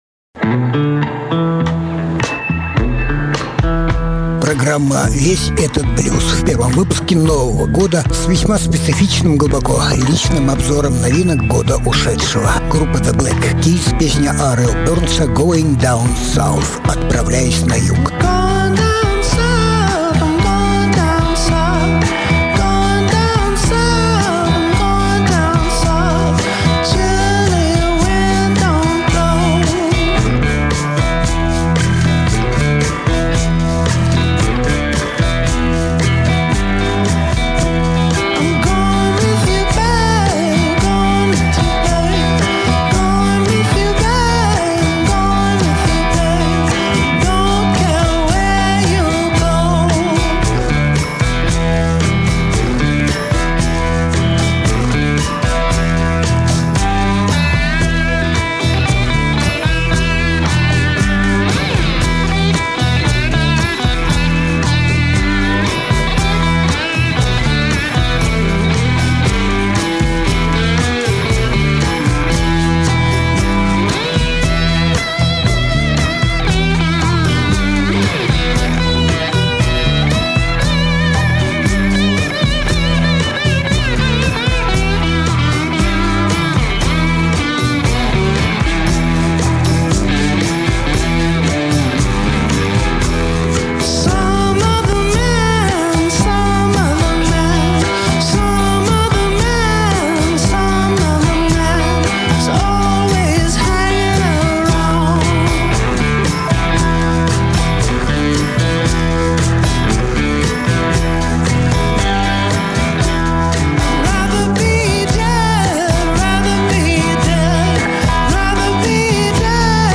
Различные альбомы Жанр: Блюзы и блюзики СОДЕРЖАНИЕ 03.01.2022 Обзор блюзовых пластинок